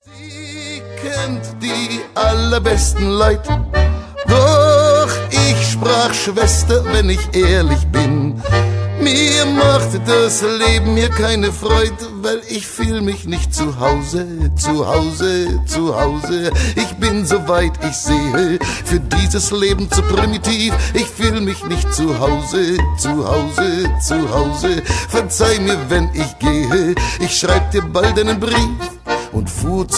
World Music From Berlin